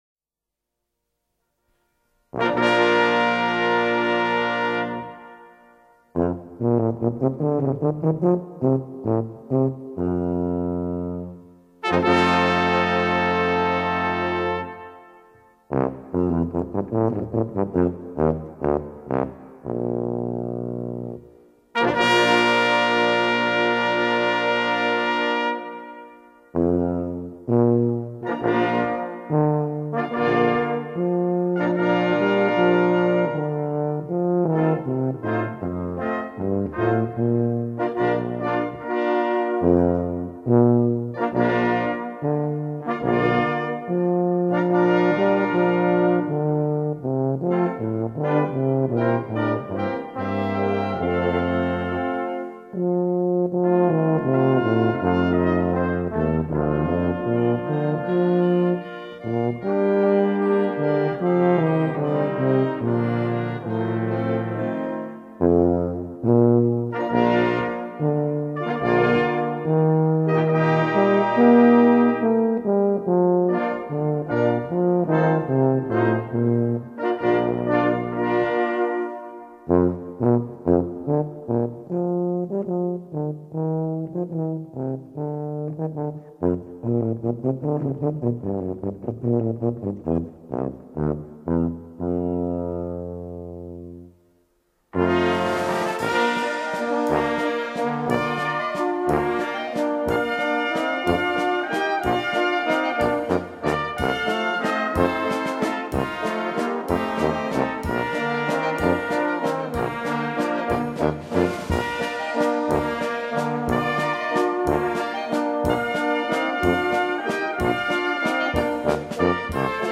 Tuba Solo